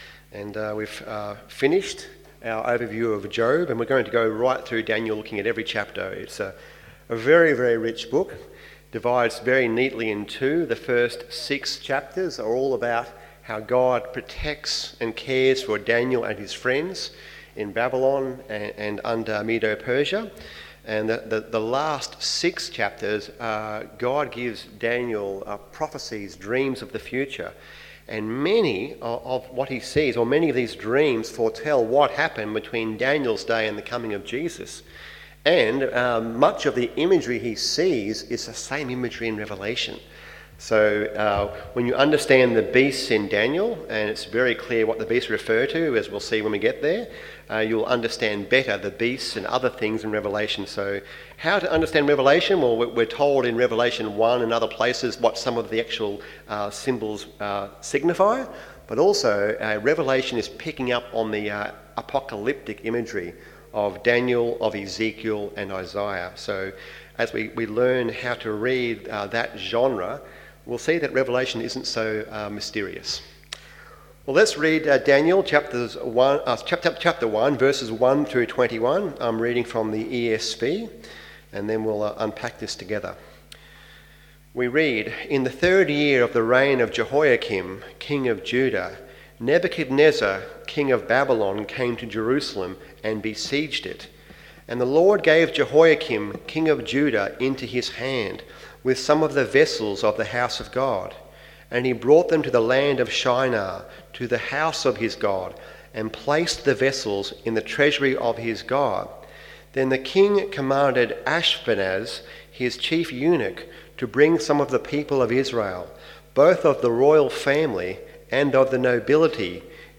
Be Strong And Courageous PM Service